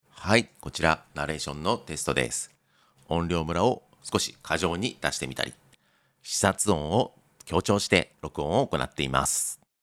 また、DynAssistはナレーションでも使用可能です。
DynAssistの効果が分かりやすくなるように、音量のばらつきやブレス音を意図的に過剰に録音しましたが、適用後はこれらも聴きやすくなっていることが感じられたかと思います。
Voice-DynAssist-On.mp3